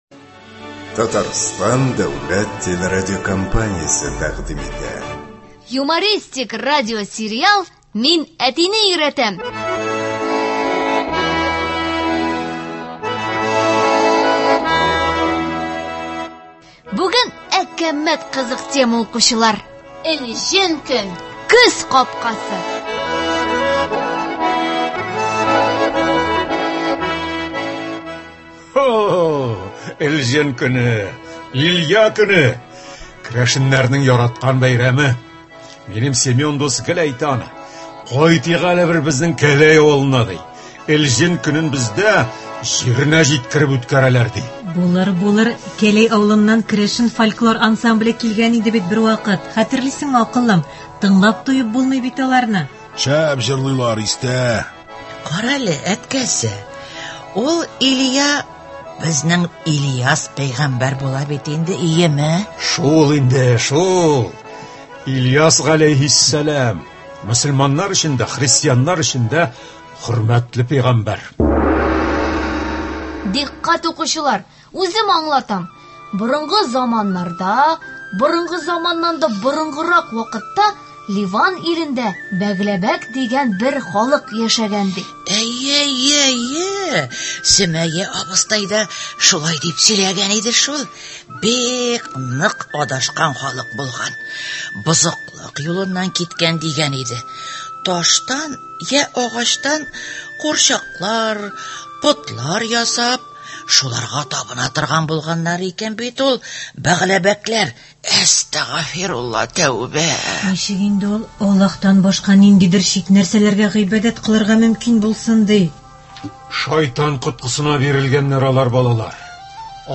Эфирда — юмористик радиосериалның 85 нче сериясе